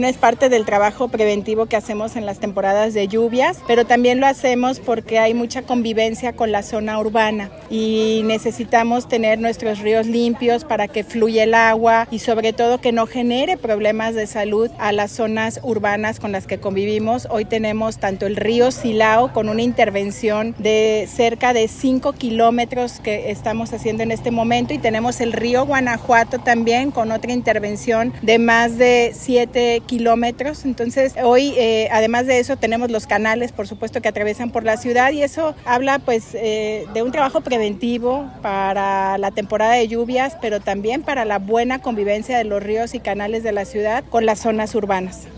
AudioBoletinesSustentabilidad
Lorena Alfaro García, presidenta de Irapuato